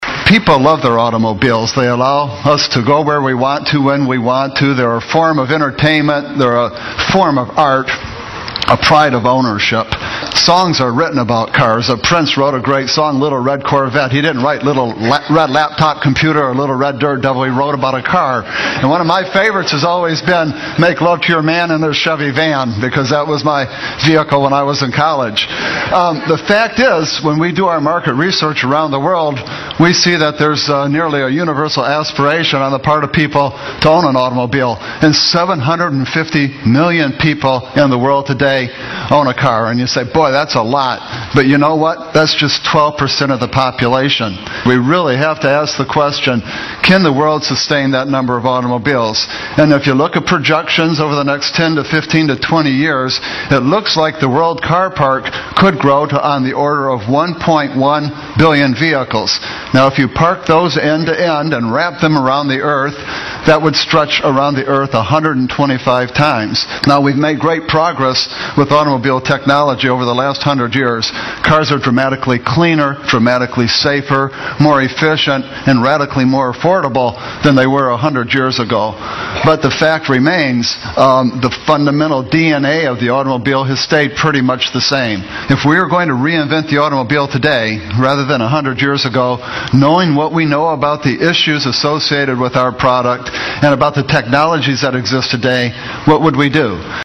财富精英励志演讲 第121期:汽车的未来(1) 听力文件下载—在线英语听力室